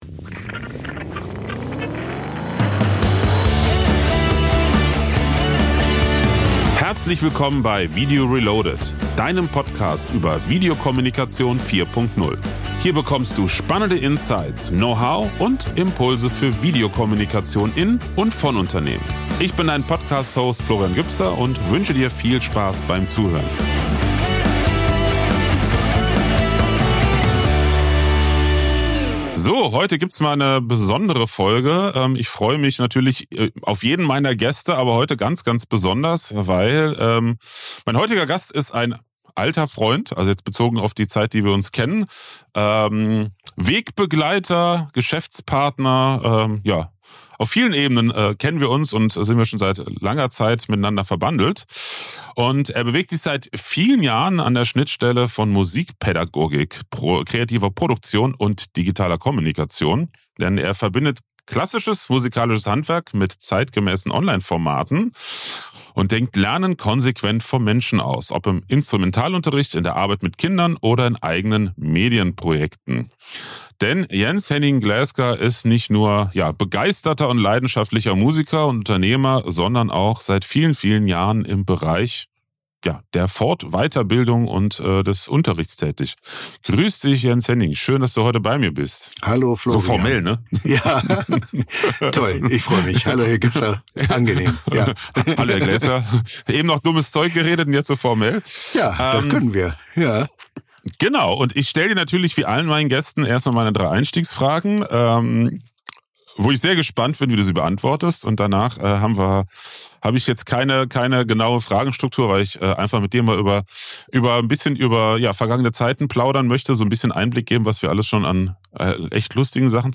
Diese Episode ist deshalb eher ein persönliches Gespräch unter Kollegen und Freunden.